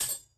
kits/Alchemist/Percs/LanFan's Kunai.wav at 32ed3054e8f0d31248a29e788f53465e3ccbe498